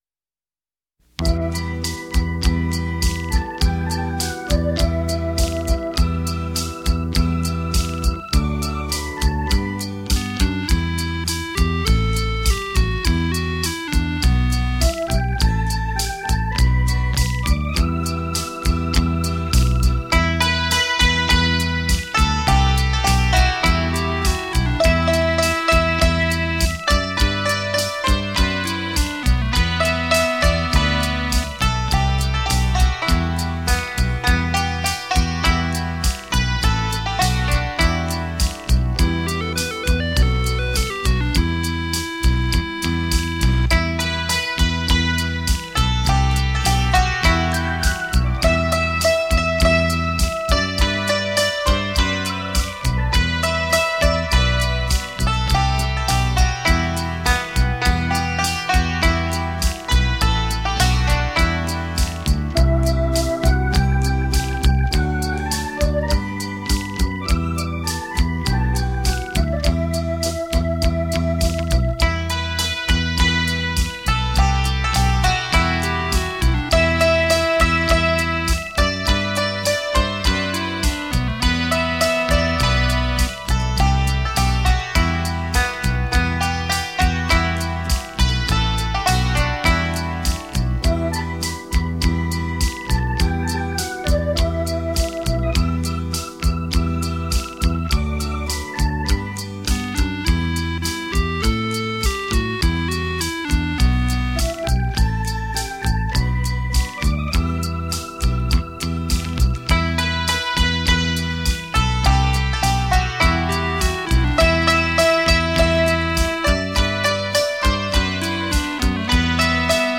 专辑音乐：扬琴、电子琴